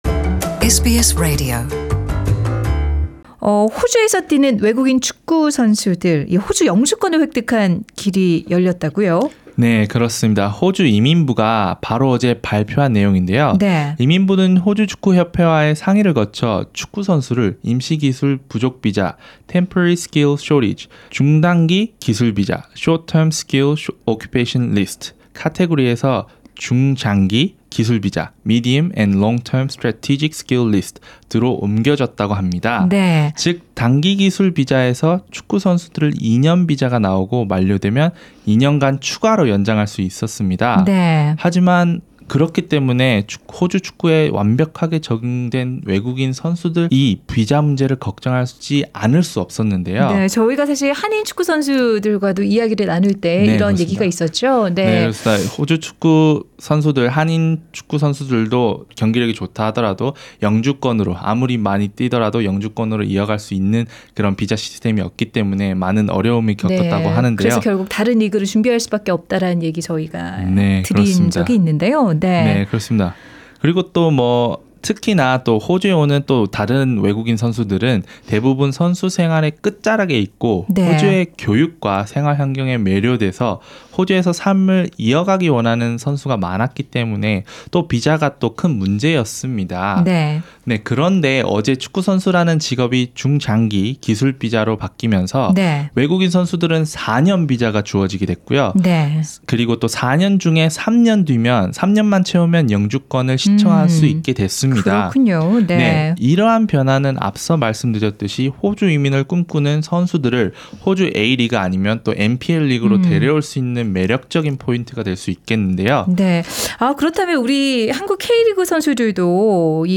Soccer TONG is a sports segment that proves how everything leads to soccer.